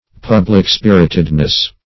Pub"lic-spir`it*ed*ness, n.